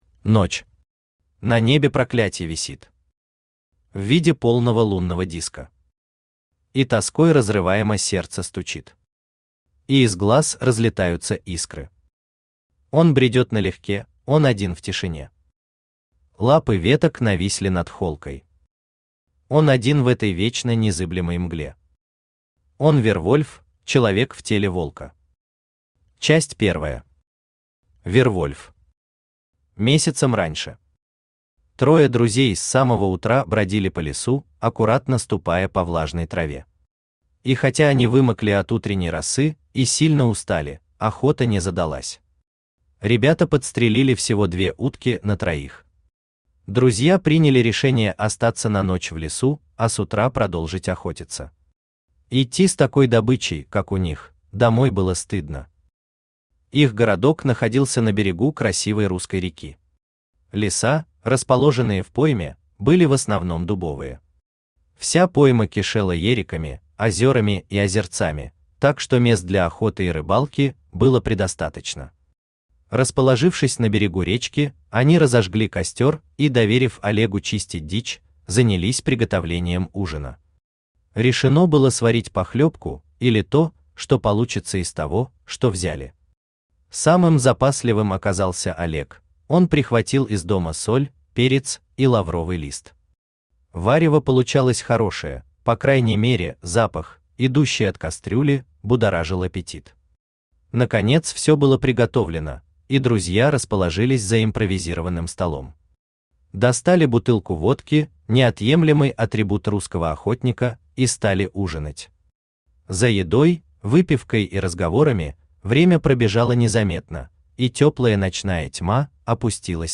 Аудиокнига Взгляд волка | Библиотека аудиокниг
Aудиокнига Взгляд волка Автор Вячеслав Викторович Образцов Читает аудиокнигу Авточтец ЛитРес.